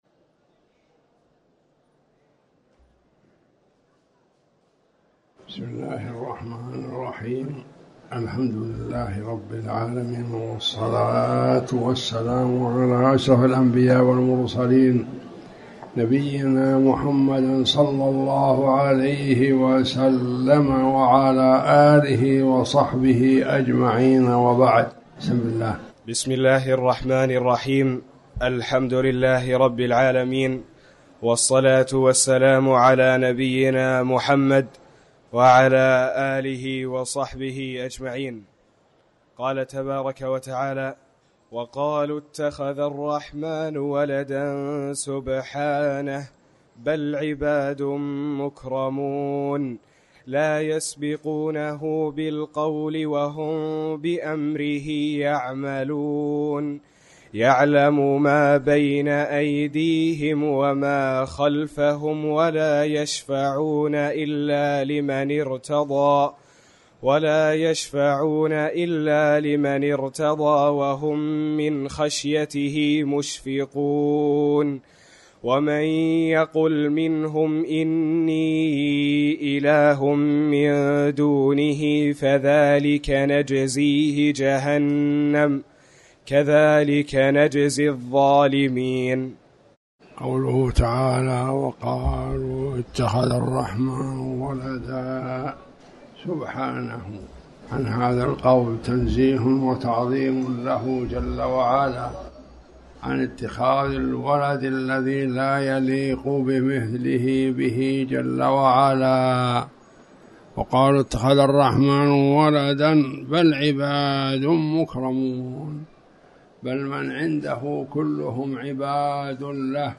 تاريخ النشر ١ جمادى الآخرة ١٤٤٠ هـ المكان: المسجد الحرام الشيخ